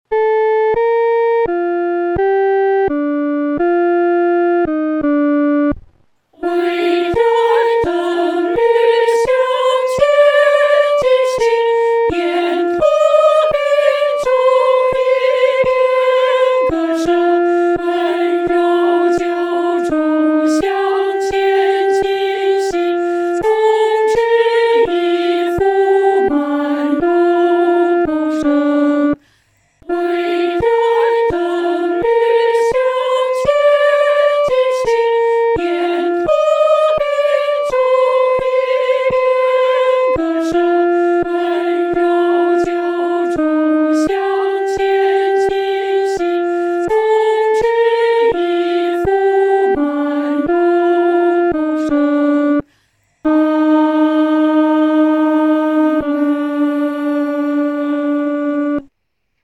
合唱
女高